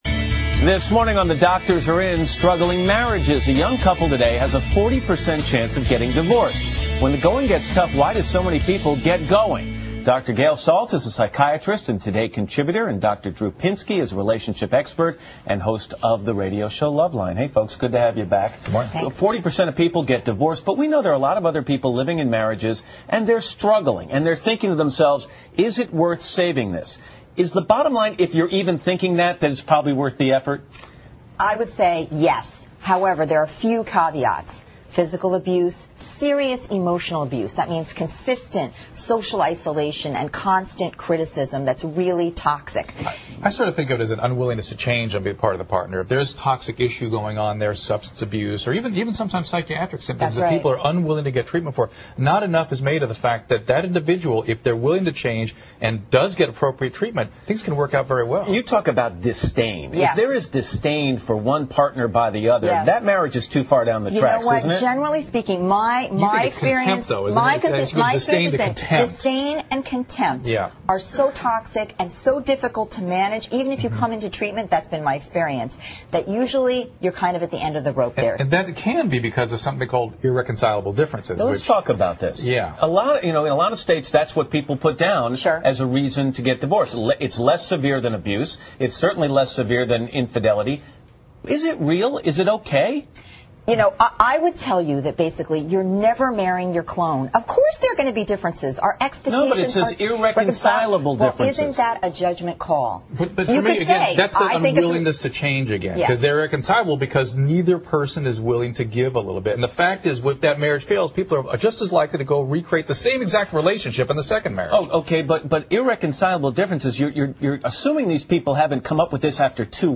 访谈录 Interview 2007-05-03&05, 什么导致高离婚率？